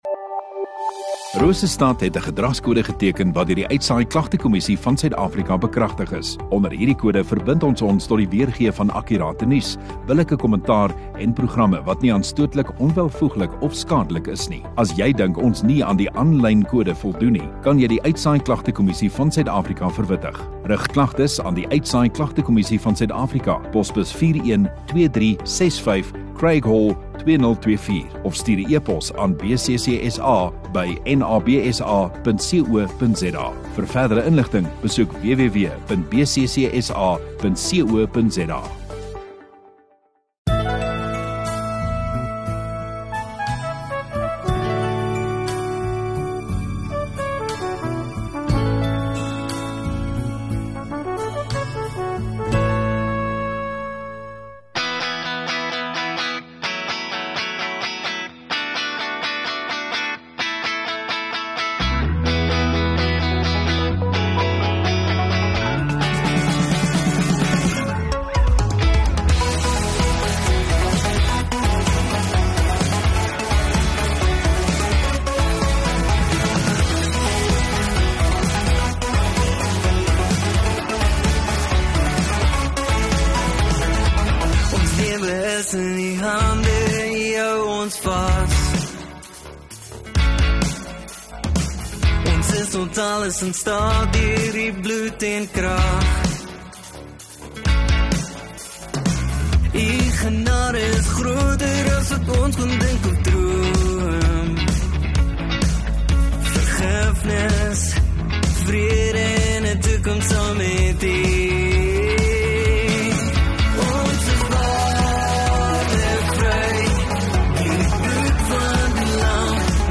25 Dec KERSFEES Woensdagoggend Erediens